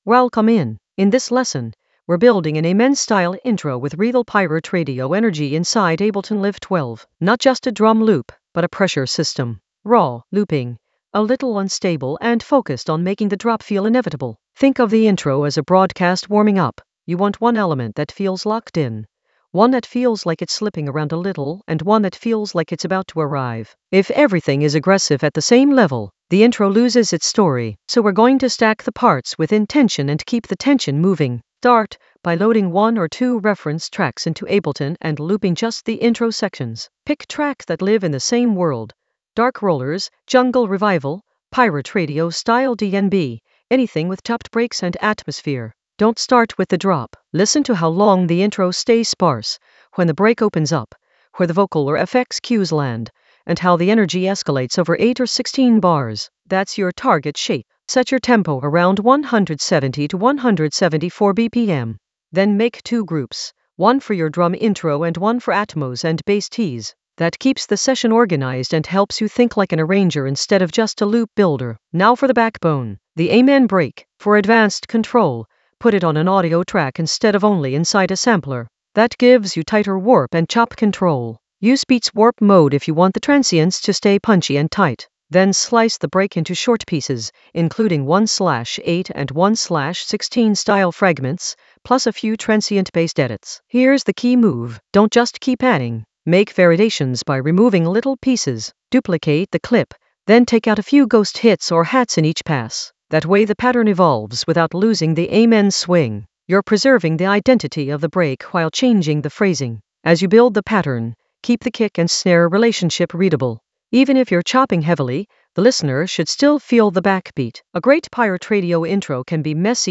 An AI-generated advanced Ableton lesson focused on Stack an Amen-style intro for pirate-radio energy in Ableton Live 12 in the Sound Design area of drum and bass production.
Narrated lesson audio
The voice track includes the tutorial plus extra teacher commentary.